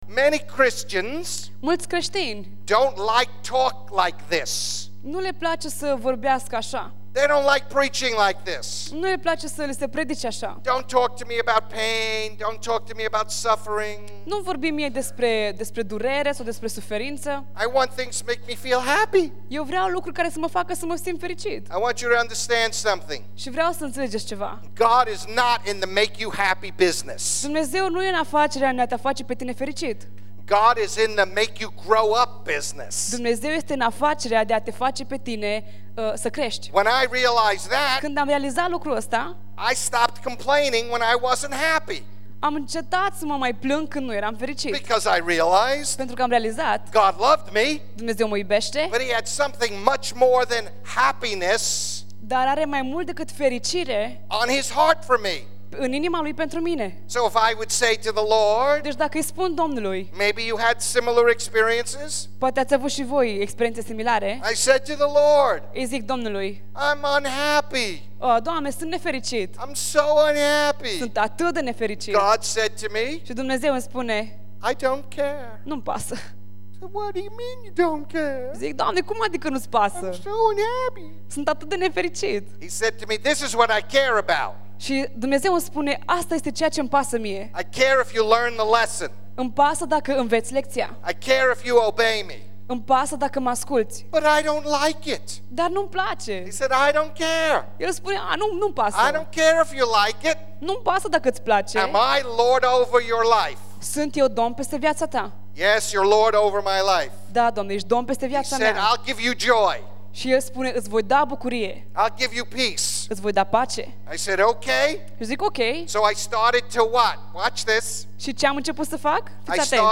Iată mai jos doar câteva afirmaţii din cadrul predicii sale.